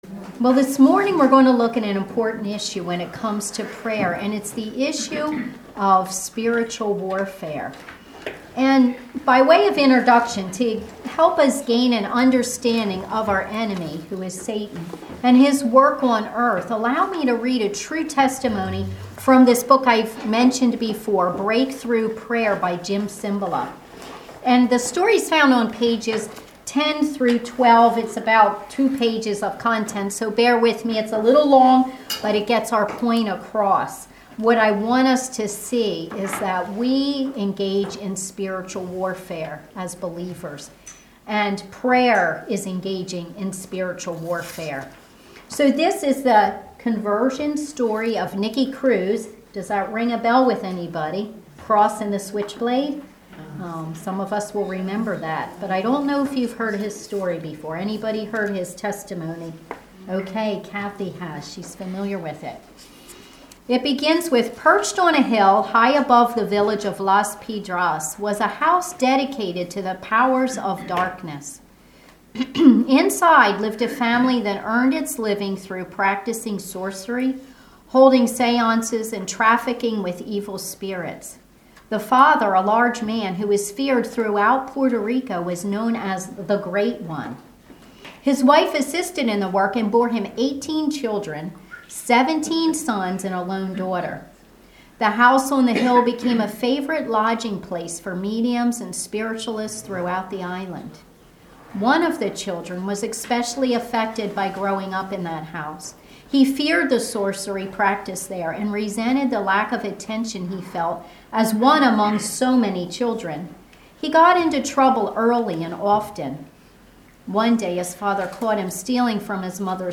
Service Type: Ladies Bible Study